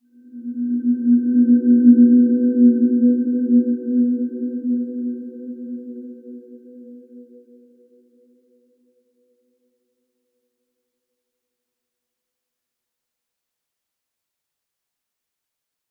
Dreamy-Fifths-B3-mf.wav